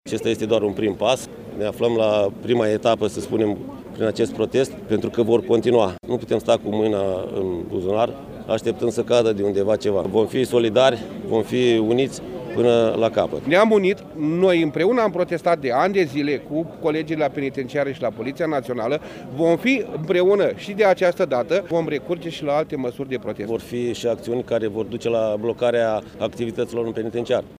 10-ian-ora-14-vox-pop.mp3